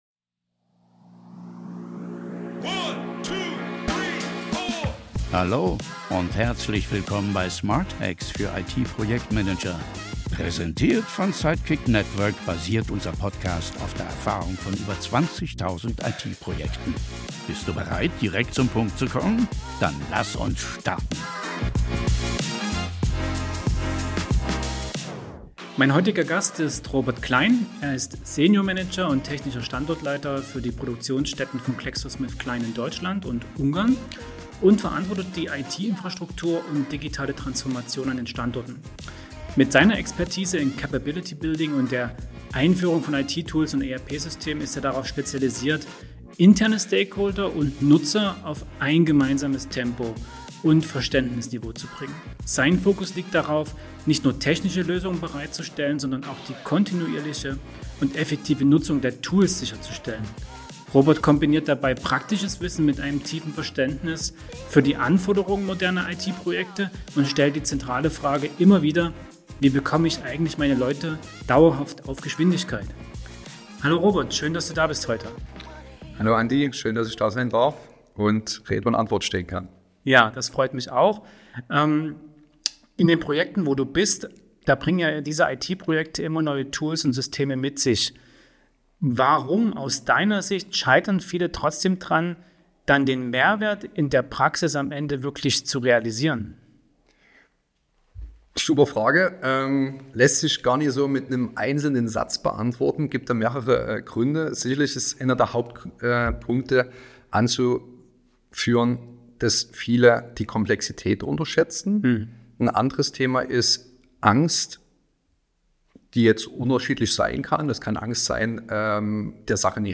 Im Gespräch erläutert er, warum IT-Projekte oft an unterschätzter Komplexität und fehlender Änderungsbereitschaft scheitern und zeigt drei praxisnahe Hacks: (1) Personas sauber definieren und jedem Stakeholder klarmachen, was für ihn persönlich drin ist; (2) Showcases und Sandboxes aufsetzen, in denen Anwender mit ihren eigenen Daten gefahrlos experimentieren; (3) ein mehrstufiges Key-User-Framework etablieren, das Power- und Senior-User systematisch in Support-, Schulungs- und Verbesserungsprozesse einbindet. So entsteht ein Pull-Effekt, der Akzeptanz fördert und kontinuierliche Optimierung ermöglicht.